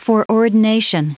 Prononciation audio / Fichier audio de FOREORDINATION en anglais
Prononciation du mot foreordination en anglais (fichier audio)